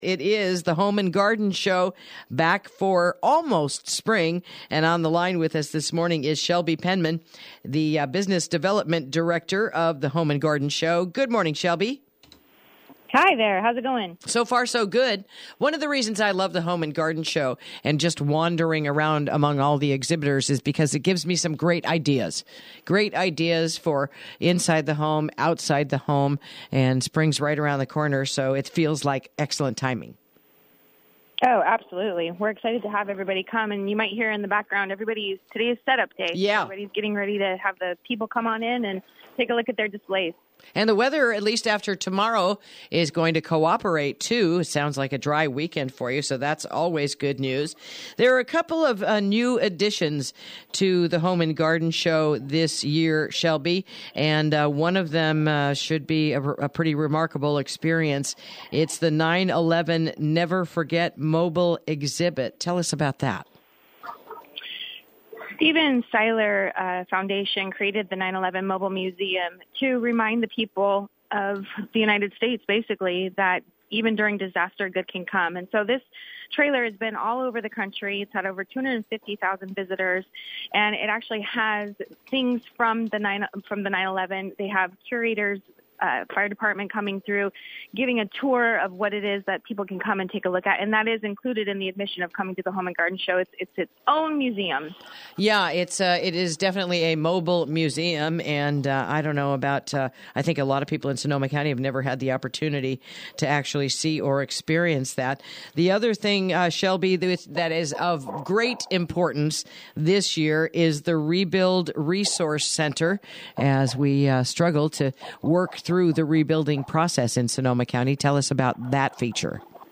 Interview: The 30th Annual Home and Garden Show Arrives This Weekend